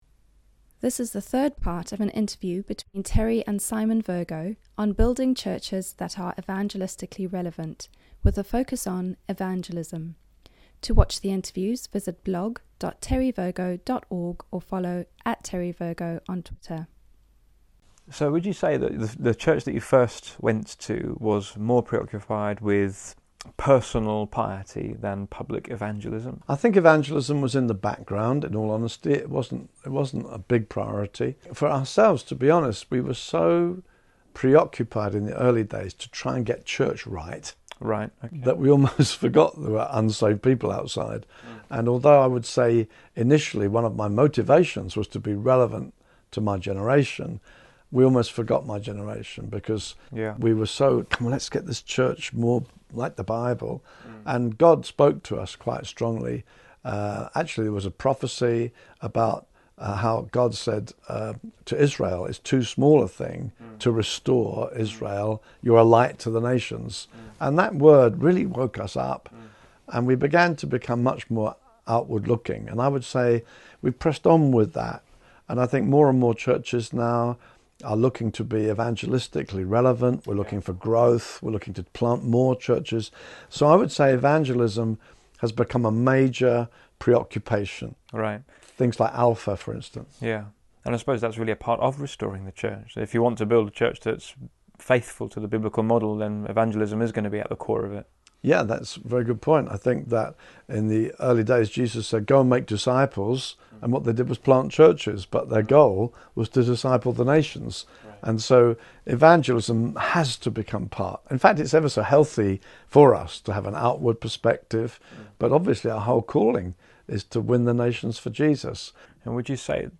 Interview+1c+Being+evangelistic.mp3